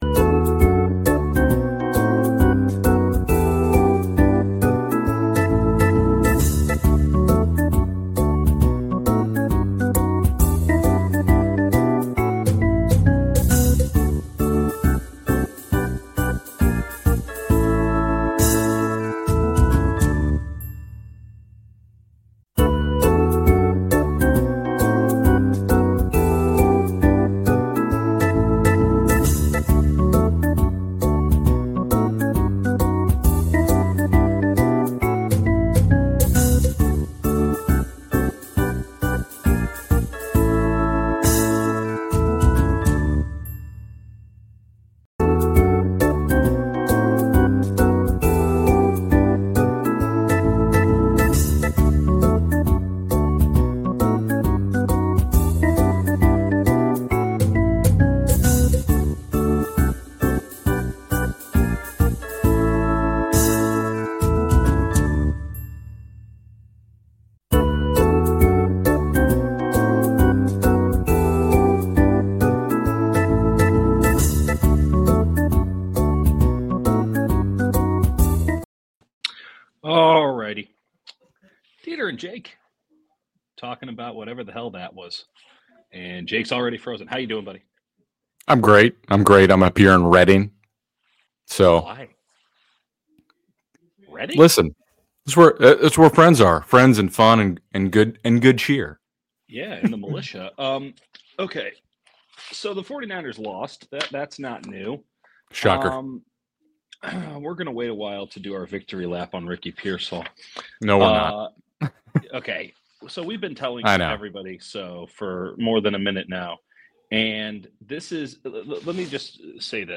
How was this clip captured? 49ers-Lions Postgame LIVE